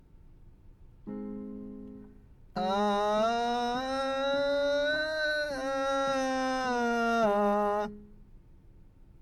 音量注意！
地声の練習をしている際に、音高が上がってくるとこんな感じで喉が苦しくなり音が届かなくなったり、同時に声量まで落ち込んでしまうという場合、ひとまず声量だけは確保できるように調整しましょう。